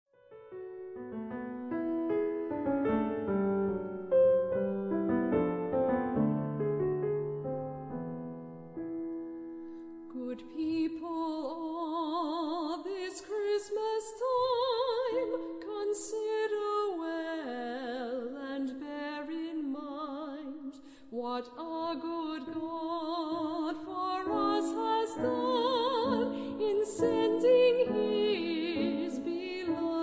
Irish Christmas Carol
mezzo-soprano
pianist